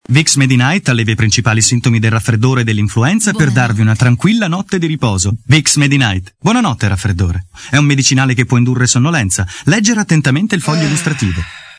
Demo Audio Pubblicità Voiceover